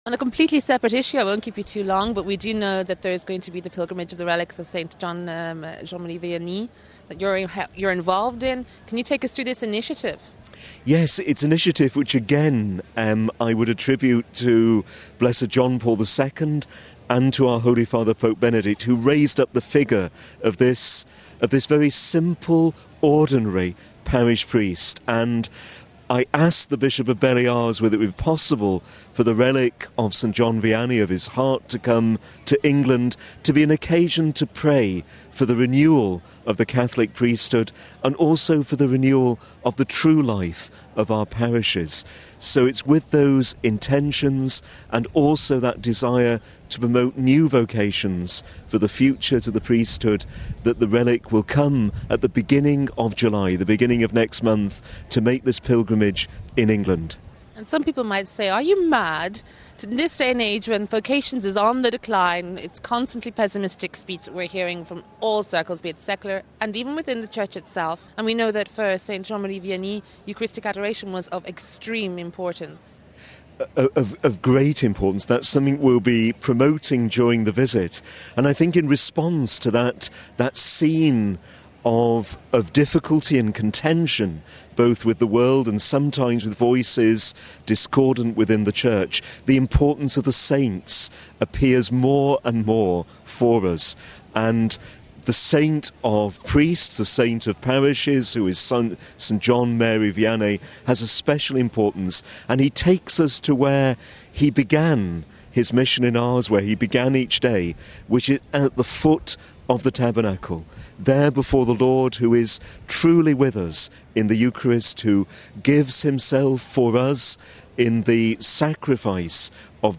He told